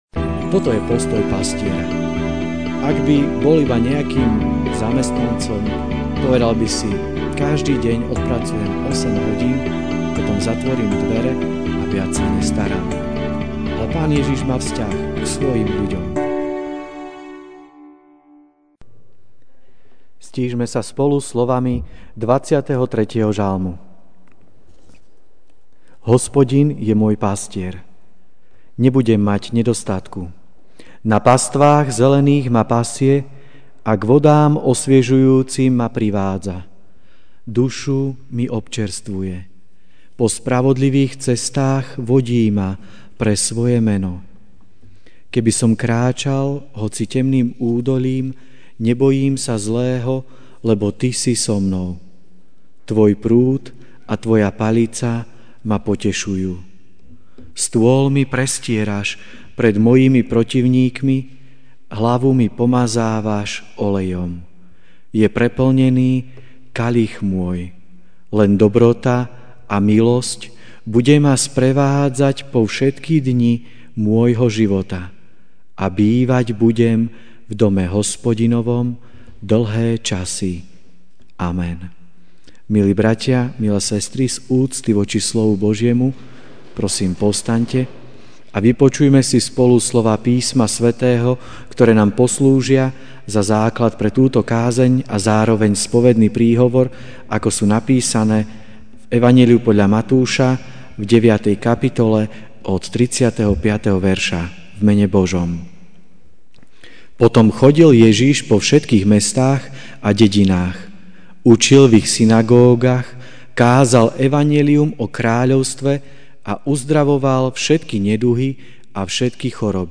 Večerná kázeň: Pastier, nie nájomník (Mt 9, 35-38) Potom chodil Ježiš po všetkých mestách a dedinách, učil v ich synagógach, kázal evanjelium o kráľovstve a uzdravoval všetky neduhy a všetky choroby.